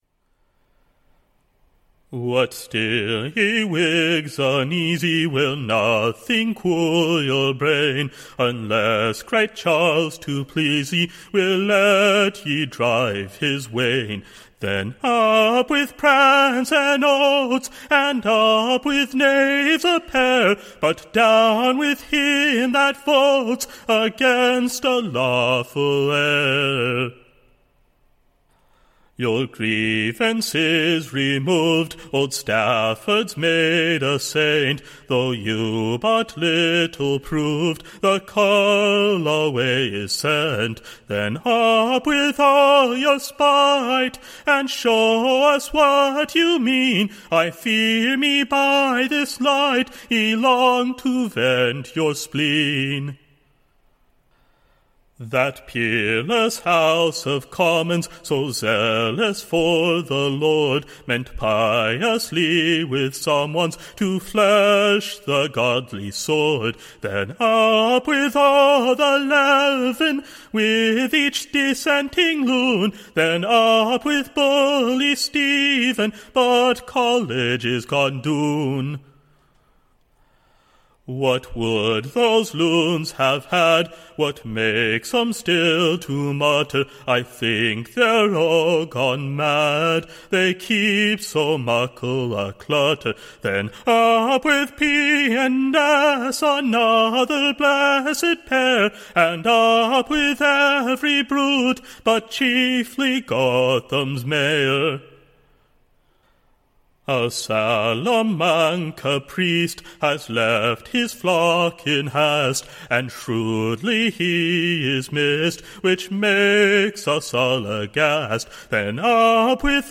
A TORY in a Whig's Coat: / A New English BALLAD